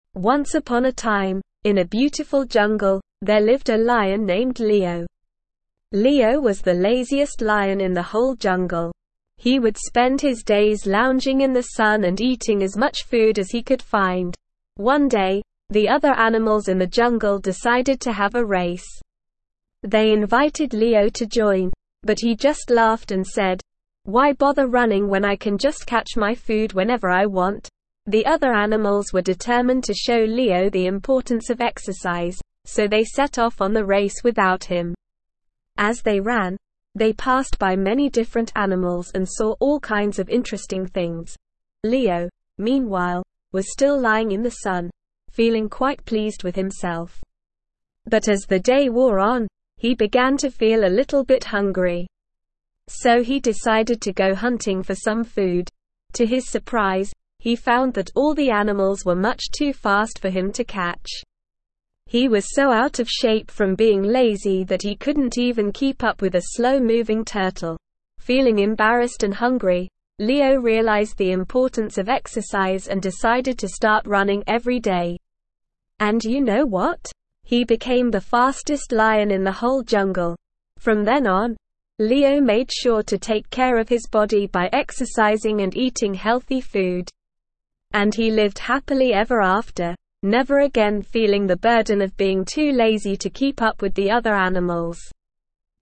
Slow
ESL-Short-Stories-for-Kids-SLOW-reading-The-Lazy-Lion.mp3